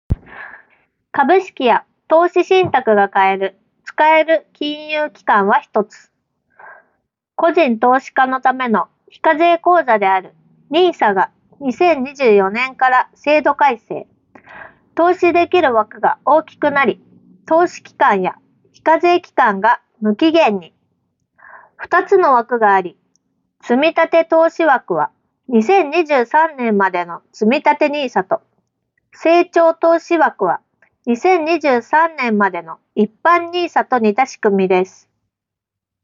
▼MEMSマイクで収音した声
全体的にややくぐもった印象はあるものの、発言内容が聞き取りにくくなるようなことはなく、ゲーム中のボイスチャットや普段の通話用途であれば問題なく使えるレベルだと感じました。
空調音など周囲の環境ノイズも比較的抑えられている印象があり、周囲の雑音に声が埋もれにくいのも好印象。